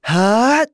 Lucias-Vox_Casting1.wav